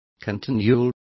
Complete with pronunciation of the translation of continual.